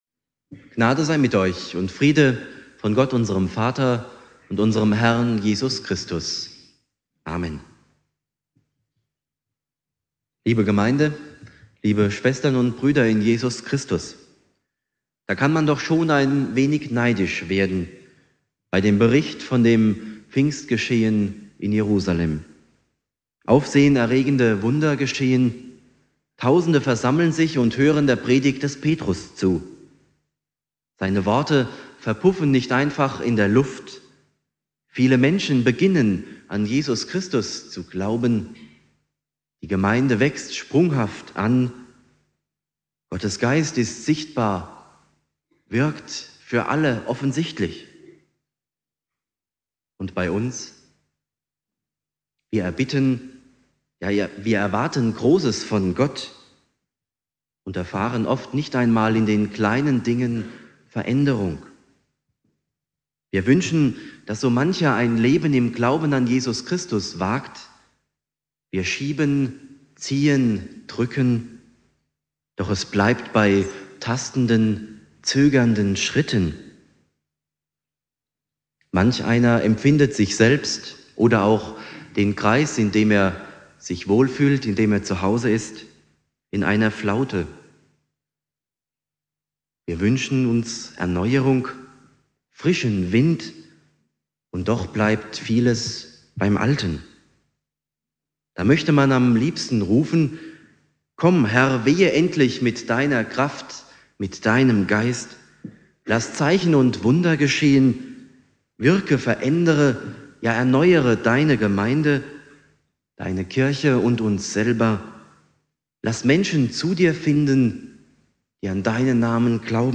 Predigt
Pfingstsonntag